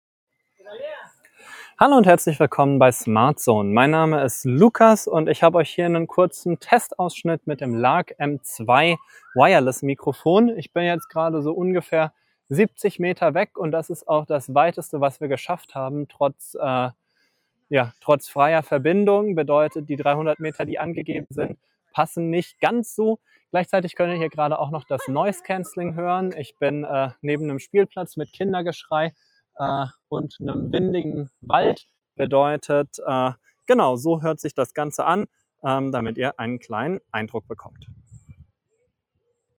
In der Praxis kommen wir da bei weitem nicht hin – nach ca. 70m auf freiem Feld ist Schluss und mit zwei Mauern dazwischen kommt die Entfernung nicht über 10m hinaus.
So ist der gesamte Sound etwas flach und hat wenig Dynamik. Bei hohen Lautstärken rutscht er auch schnell ins Blecherne ab.
Mit aktiviertem Noise Cancelling wird der Ton abermals etwas stumpfer und büßt auch an Lautstärke ein.
Lark-M2S-Hollyland-Audio-Entfernung-und-NC.mp3